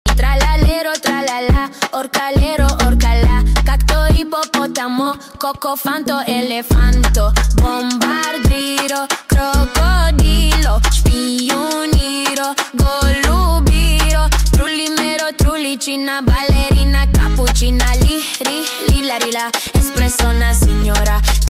Admin singing Brainrot Rap in sound effects free download